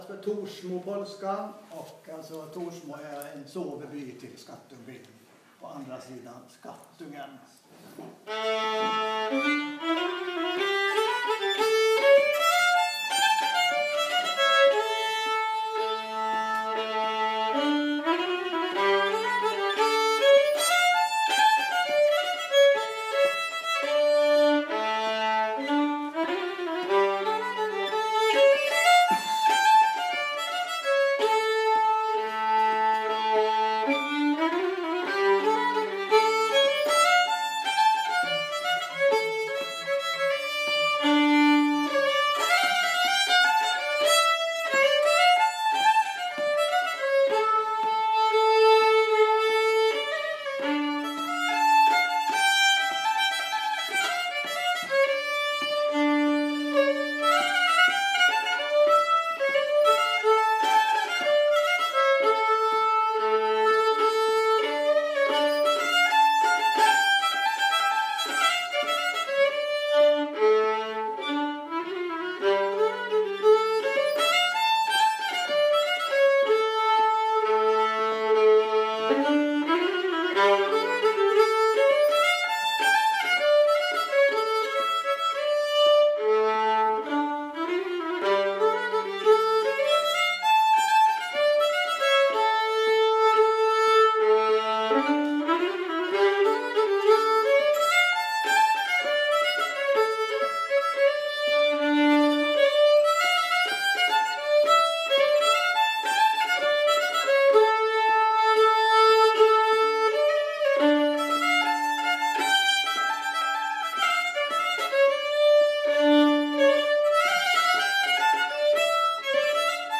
Övning Stefansgården.